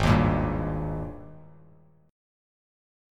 G#M7sus2 chord